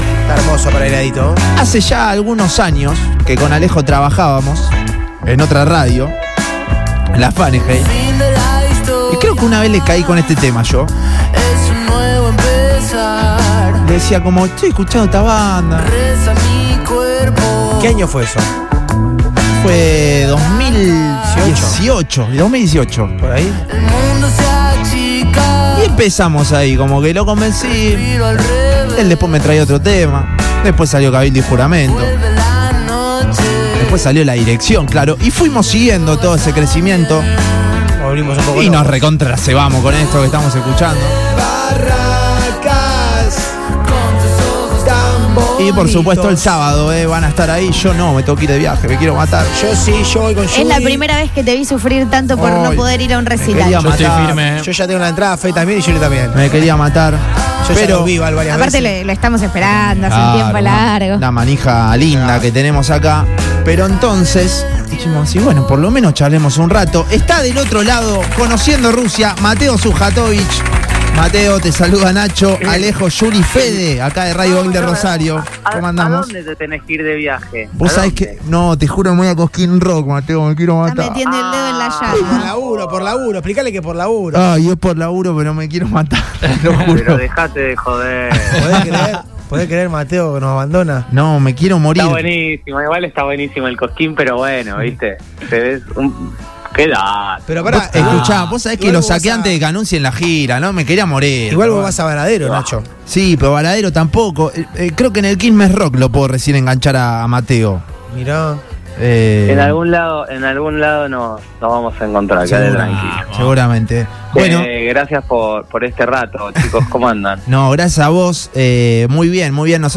El artista, que tuvo un despegue meteórico en los últimos cuatro años, también hizo un repaso de su carrera en diálogo con Boing y sus Secuaces.